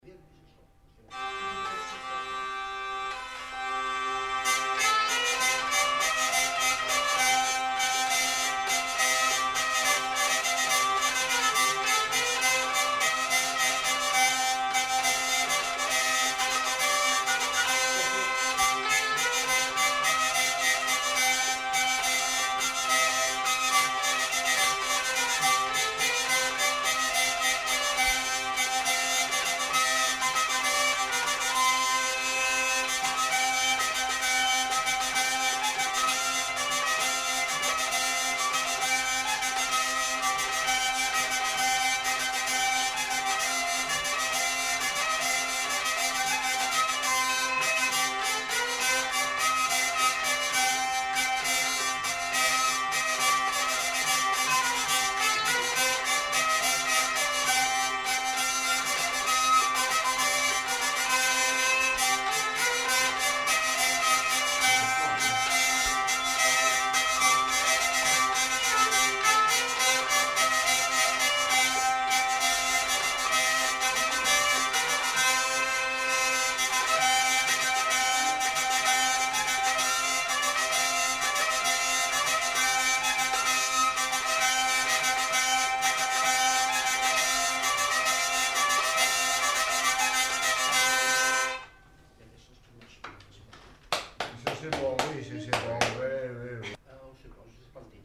Lieu : Pyrénées-Atlantiques
Genre : morceau instrumental
Instrument de musique : vielle à roue
Danse : valse